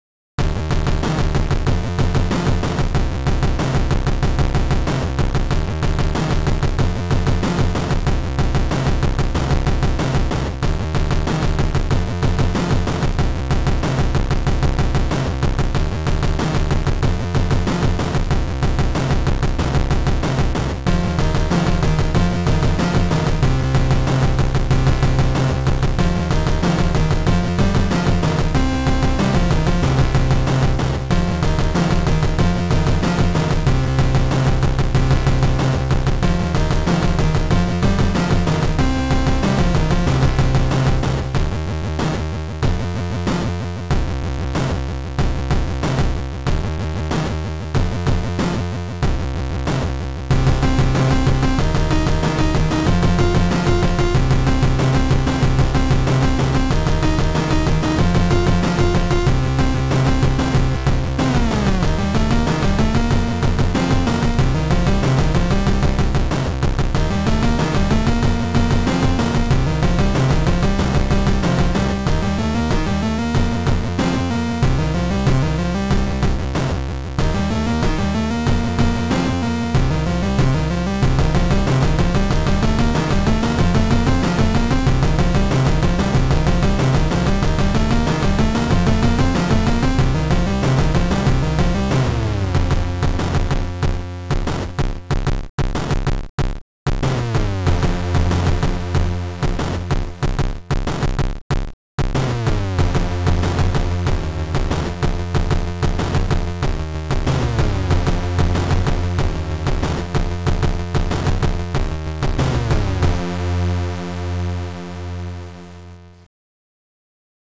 Keygen Music
we need the chiptunes